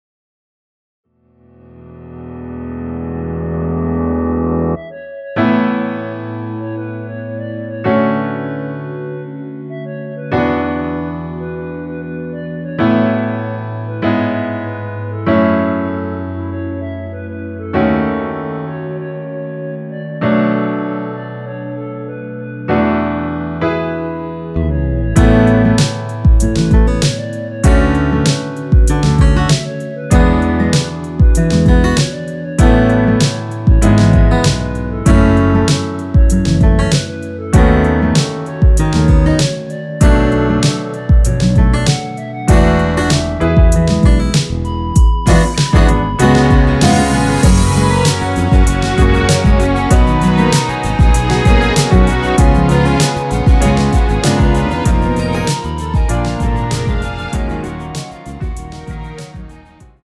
원키에서(-1)내린 멜로디 포함된 MR 입니다.
◈ 곡명 옆 (-1)은 반음 내림, (+1)은 반음 올림 입니다.
앞부분30초, 뒷부분30초씩 편집해서 올려 드리고 있습니다.
중간에 음이 끈어지고 다시 나오는 이유는